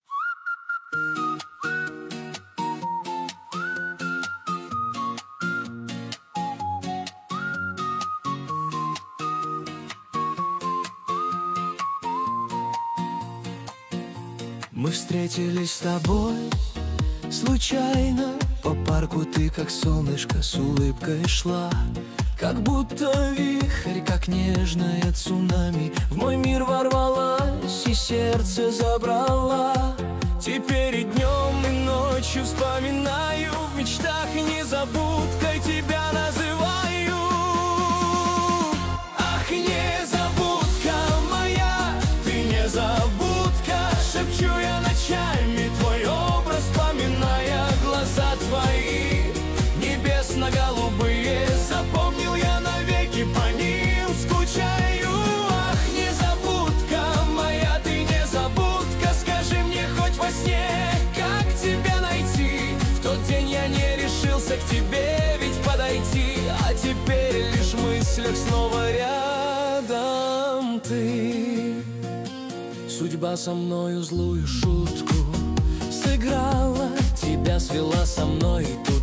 созданный с помощью искусственного интеллекта.